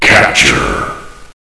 flak_m/sounds/announcer/int/capture.ogg at df55aa4cc7d3ba01508fffcb9cda66b0a6399f86